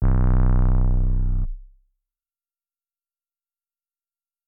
CrashDummy 808.wav